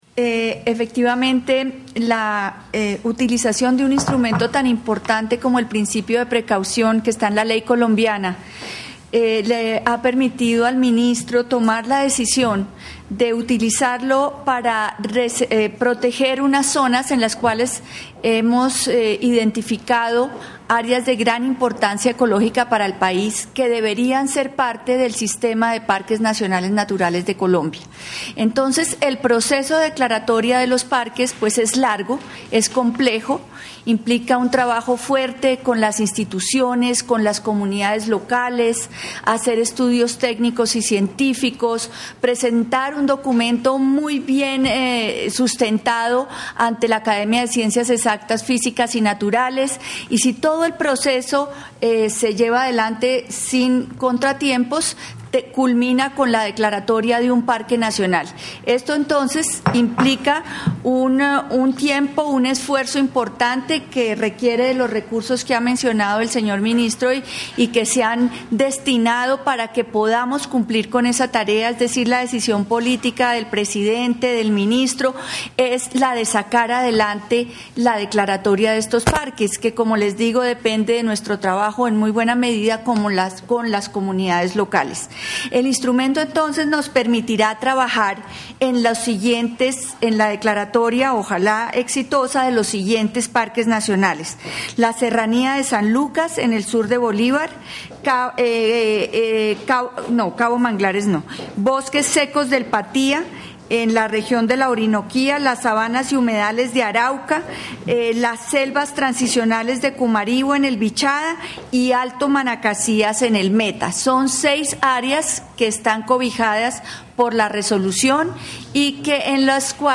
atendiendo a la importancia ecológica de estas áreas”, explicó Julia Miranda, Directora de Parques Nacionales Naturales de Colombia.
16-dir_parques_rueda_de_prensa.mp3